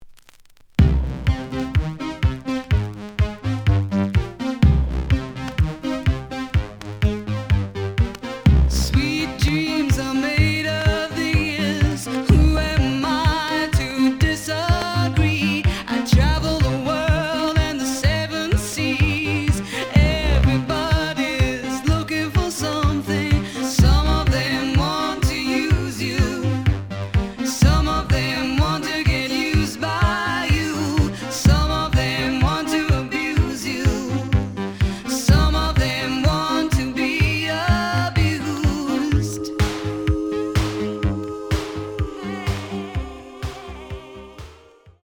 The audio sample is recorded from the actual item.
●Genre: Rock / Pop
Some click noise on beginning of B side, but almost good.